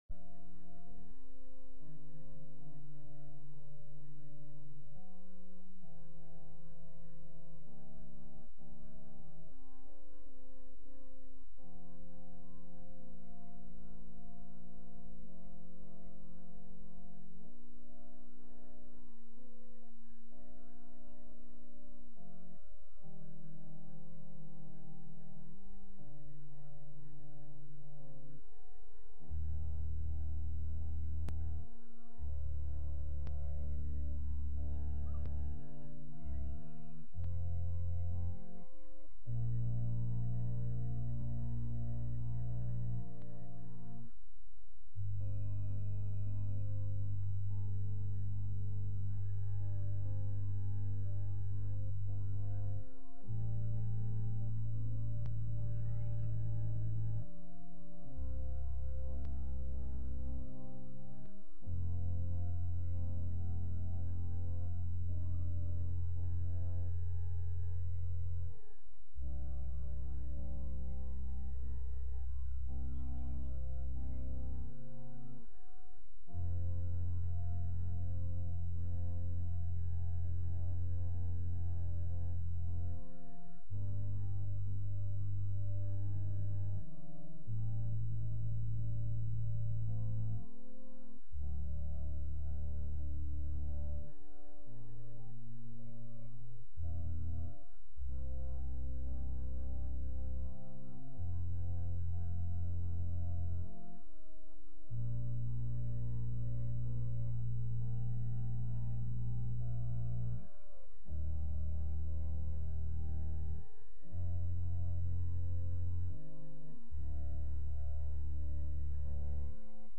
Sermons | Grace Lutheran Church
From Series: "Sunday Worship"
Sunday-Service-1-30-22.mp3